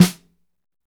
SNR P C S04R.wav